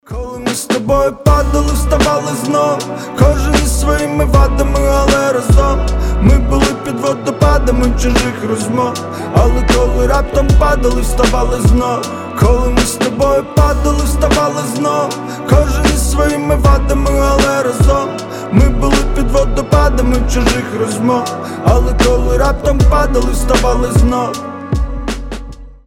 • Качество: 320, Stereo
лирика